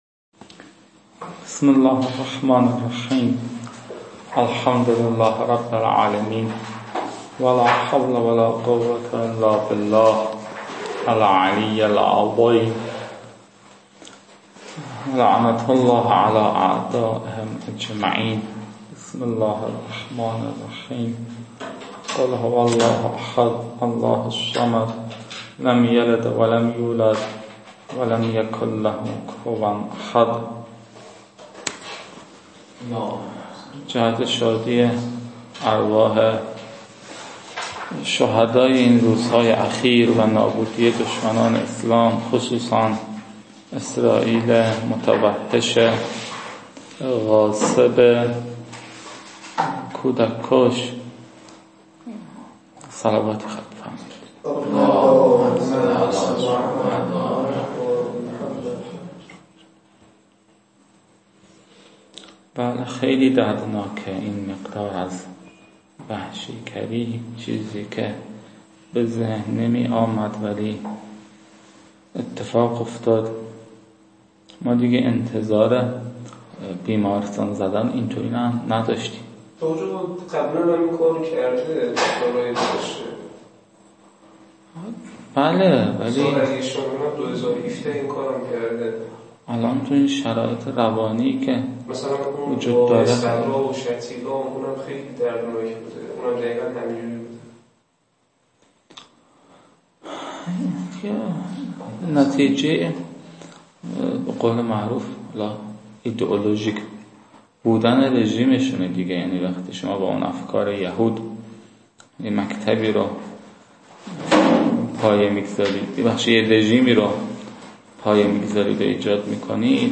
این فایل ها مربوط به تدریس مبحث برائت از كتاب فرائد الاصول (رسائل) متعلق به شیخ اعظم انصاری رحمه الله می باشد